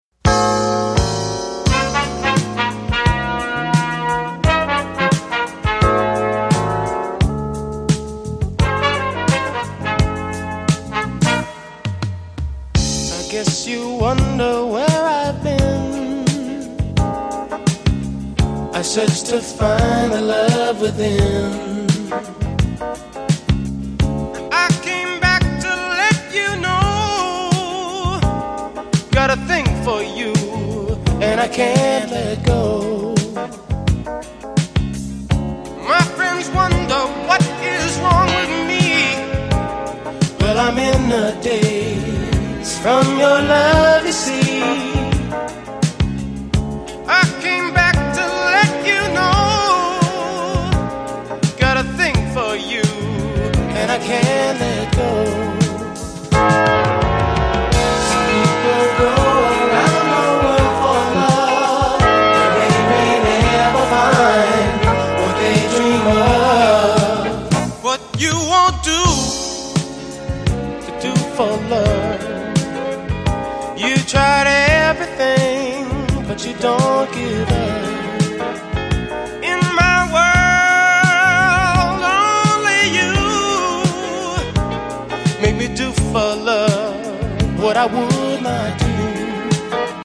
The smooth titan of blue-eyed soul